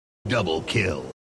halo-double-kill_26887.mp3